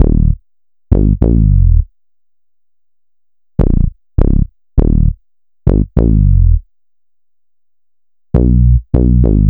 Bass.wav